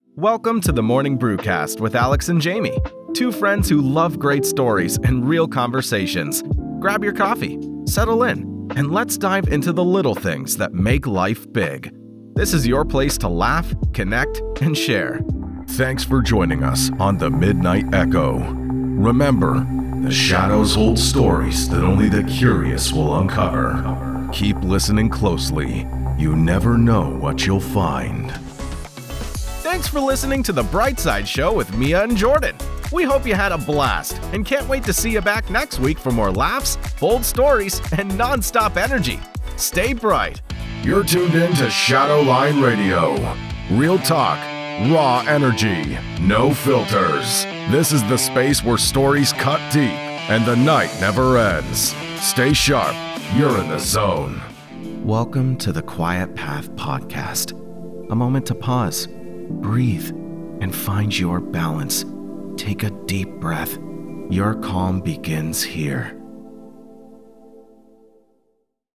Doubleur anglais (canadien)